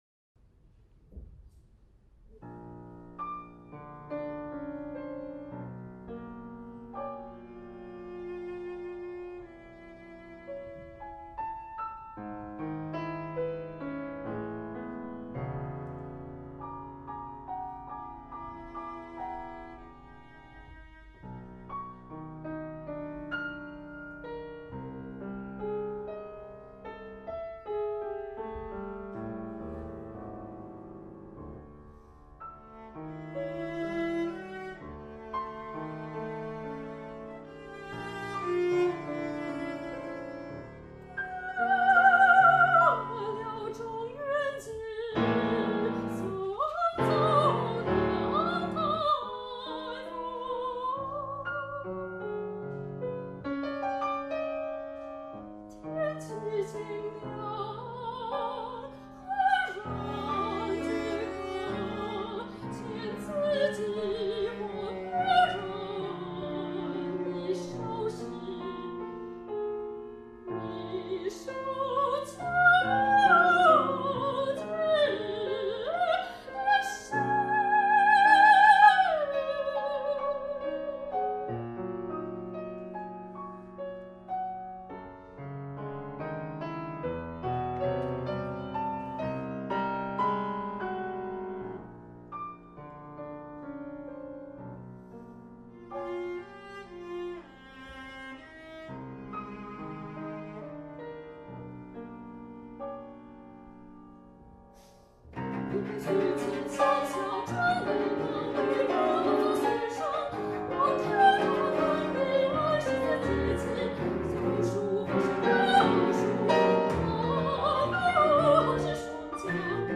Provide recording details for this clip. This recording is the live performance Bach Recital Hall, Taipei, Taiwan